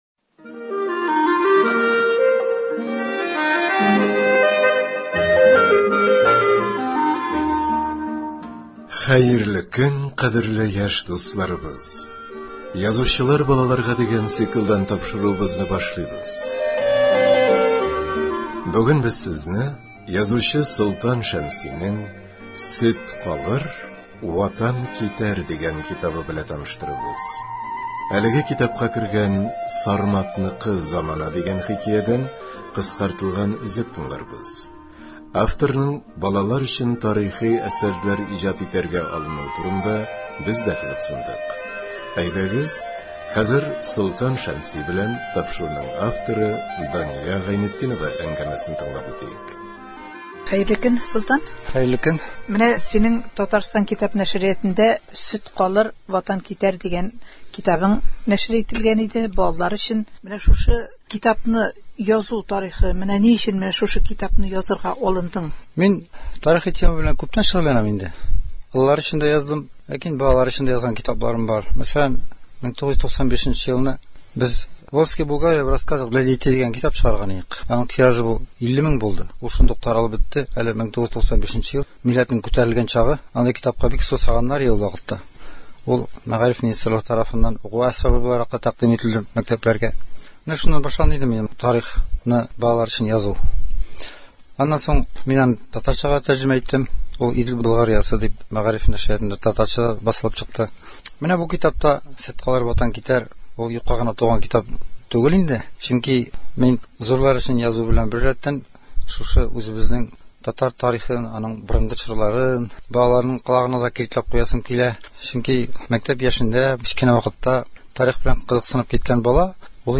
Әлеге китапка кергән “Сарматныкы замана” дигән хикәядән кыскартылган өзек тыңларбыз.